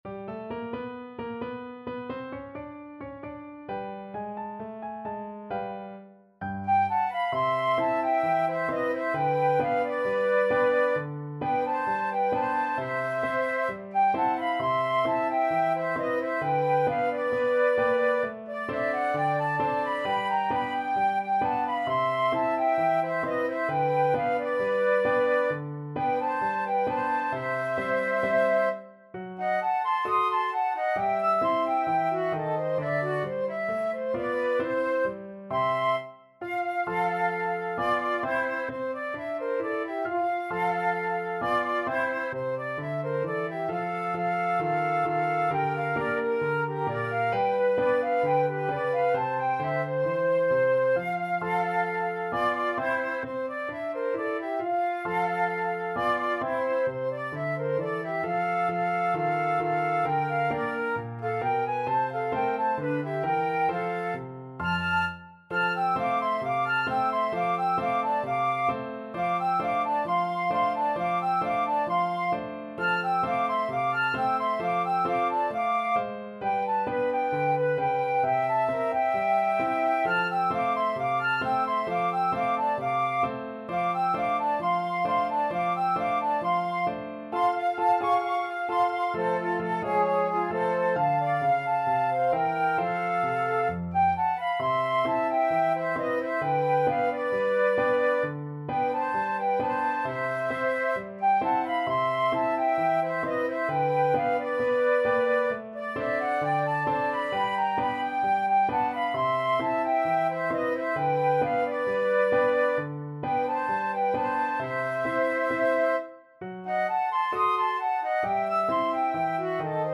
Flute 1Flute 2Piano
Allegro =132 (View more music marked Allegro)
4/4 (View more 4/4 Music)
Jazz (View more Jazz Flute Duet Music)